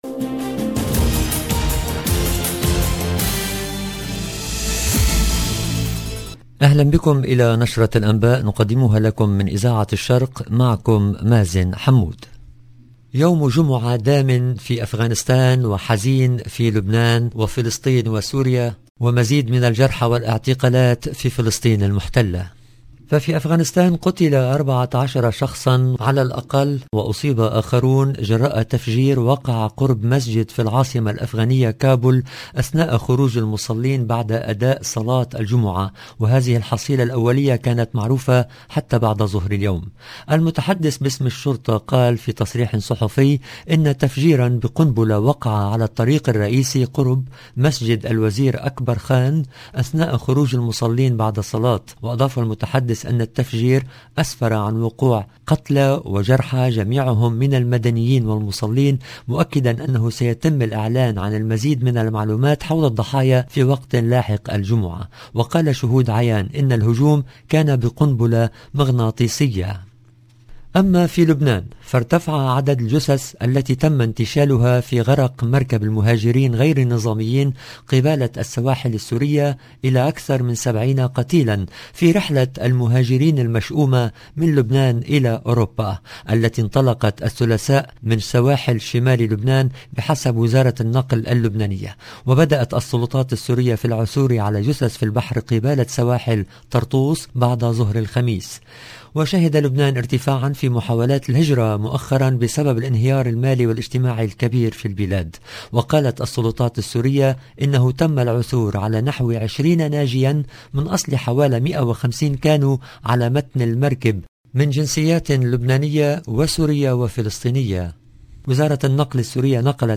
LE JOURNAL EN LANGUE ARABE DU SOIR DU 23/09/22